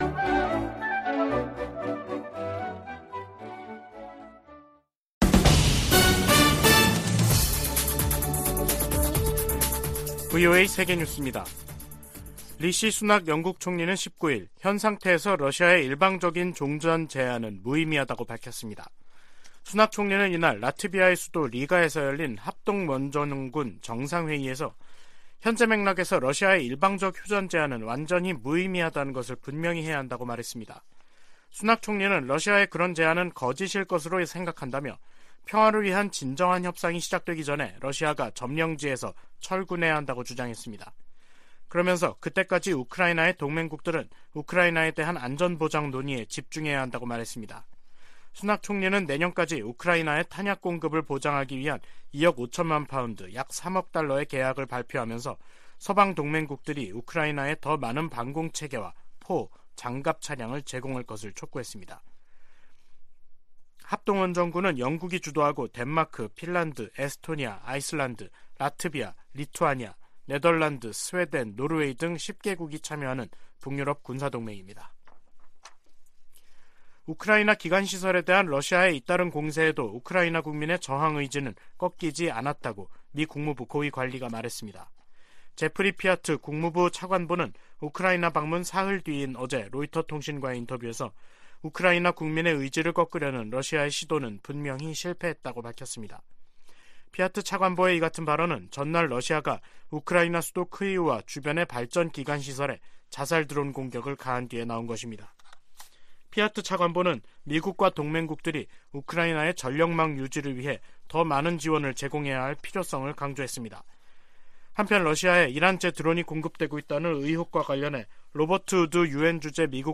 VOA 한국어 간판 뉴스 프로그램 '뉴스 투데이', 2022년 12월 20일 3부 방송입니다. 미 국무부는 북한이 정찰위성 시험이라고 주장한 최근 미사일 발사가 전 세계를 위협한다며, 외교로 문제를 해결하자고 촉구했습니다. 유엔은 북한의 최근 탄도미사일 발사와 관련해 한반도 긴장 고조 상황을 매우 우려한다며 북한에 즉각적인 대화 재개를 촉구했습니다.